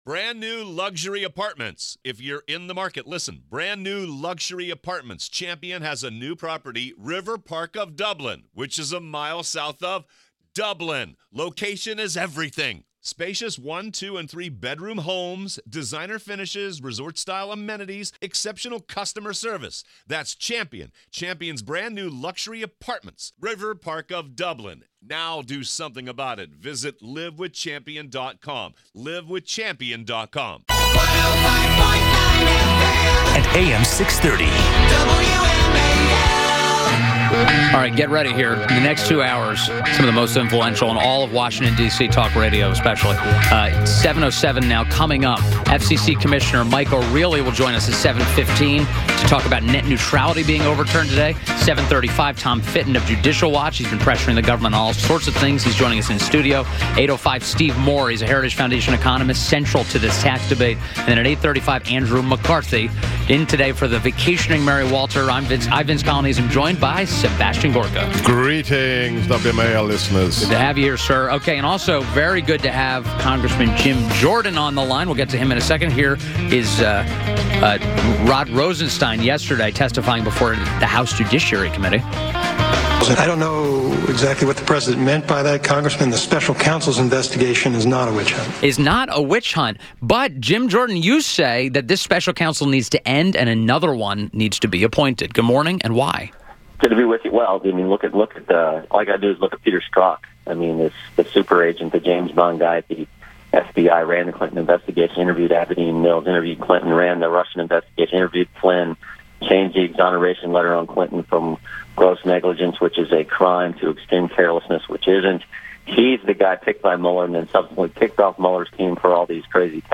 WMAL Interview - REP. JIM JORDAN - 12.14.17